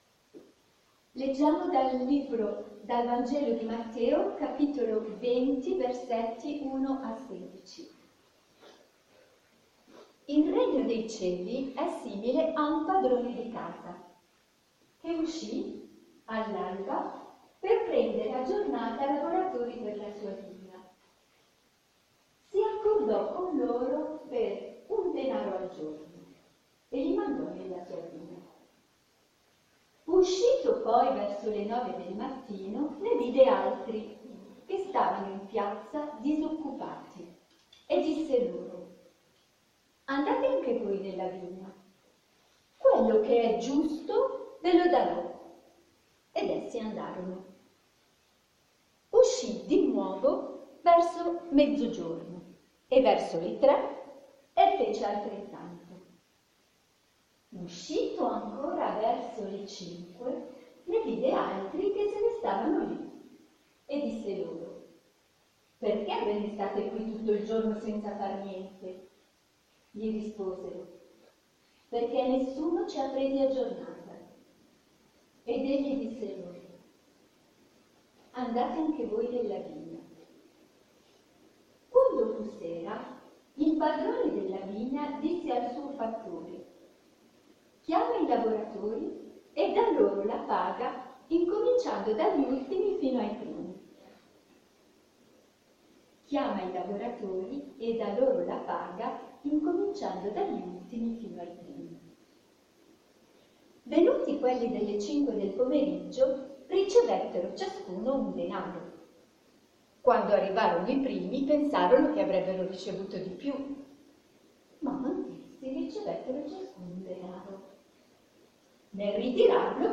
Lo scorso fine settimana, dal 3 al 5 marzo 2017, si sono svolti, presso la Casa di Spiritualità di San Martino – Castello di Vittorio Veneto, gli esercizi spirituali per adulti organizzati dall’Azione Cattolica Diocesana.
Audio meditazione EESS Adulti